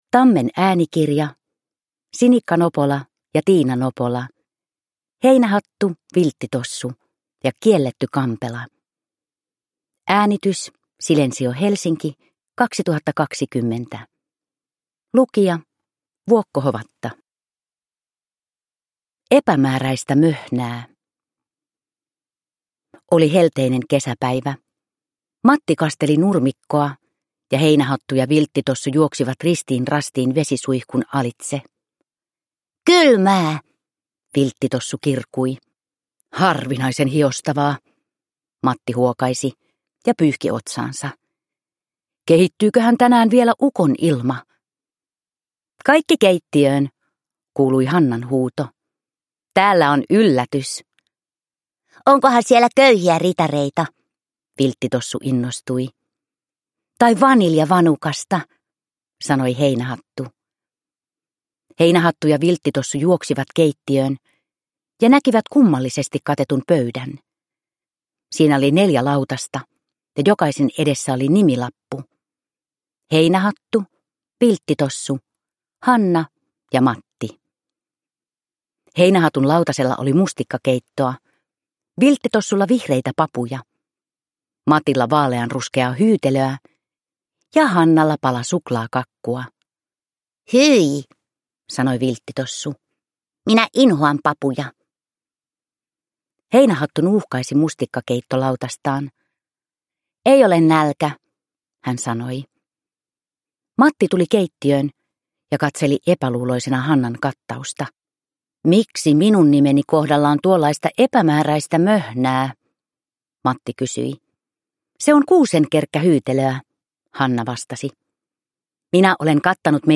Heinähattu, Vilttitossu ja kielletty kampela – Ljudbok